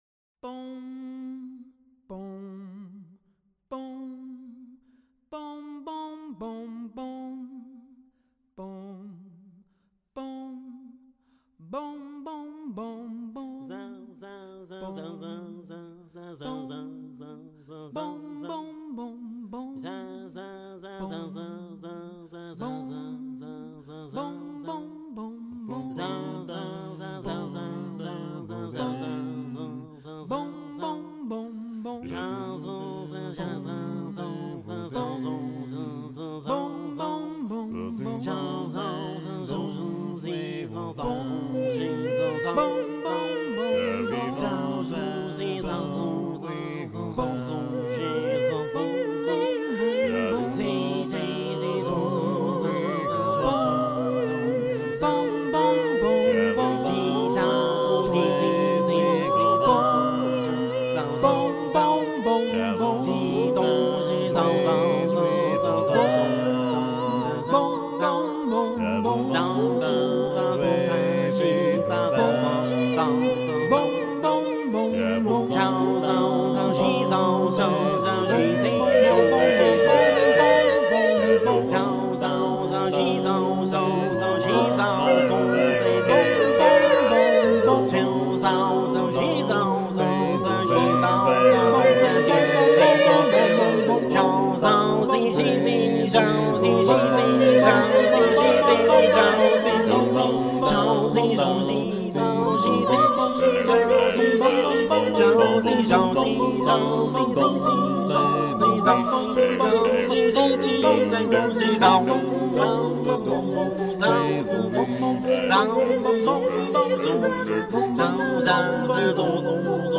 A capella choral music created through improvisation